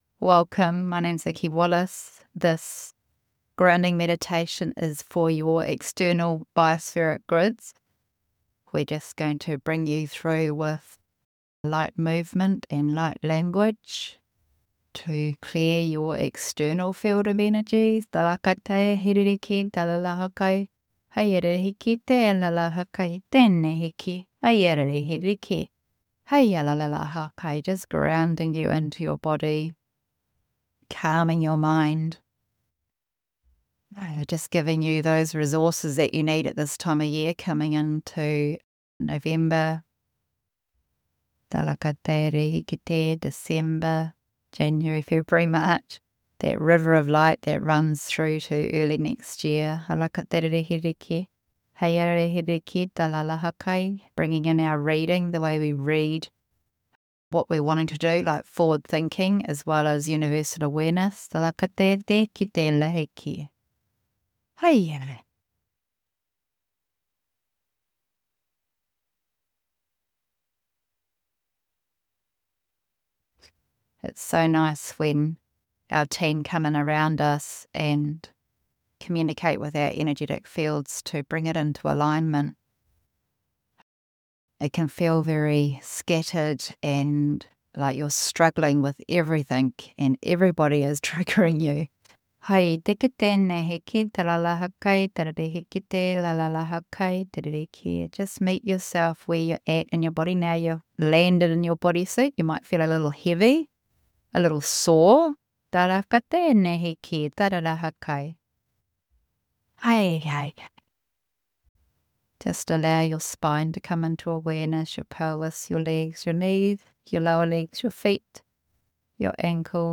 In this short guided practice, we’ll move gently (with the energy of light language and frequency, audio link below) to clear your external field, ground you into your body, and calm your mind.